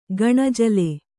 ♪ gaṇajile